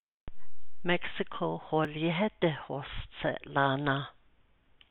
It is inserted for pronunciation purposes, but does not have meaning.